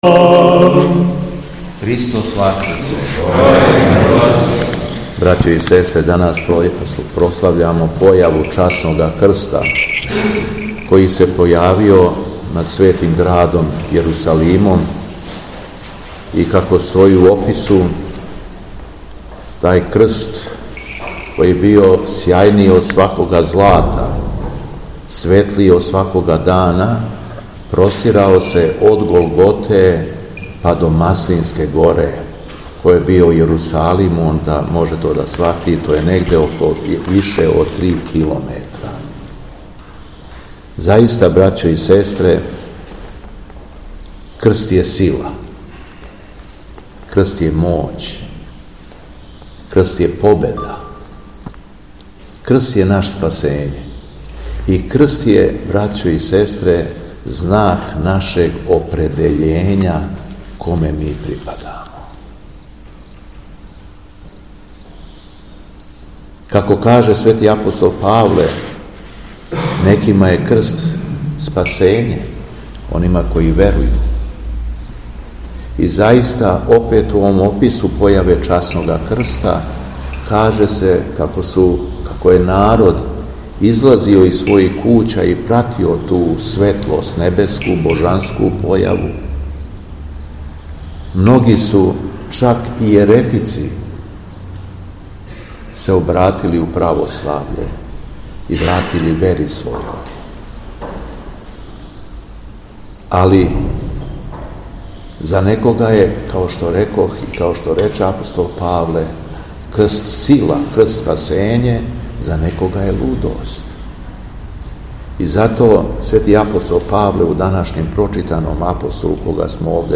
Беседа Његовог Високопреосвештенства Митрополита шумадијског г. Јована
Након прочитаног јеванђељског штива верном народу се надахнутом беседом обратио Митрополит Јован: